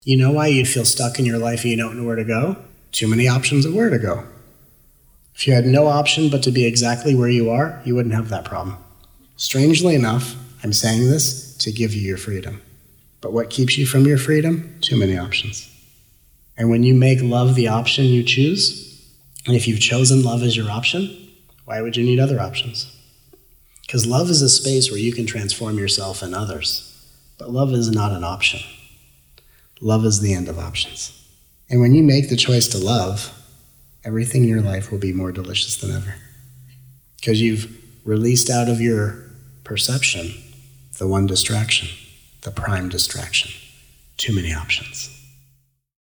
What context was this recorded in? Recorded over the course of the March 2017 "Feel Good Now" 5-day Soul Convergence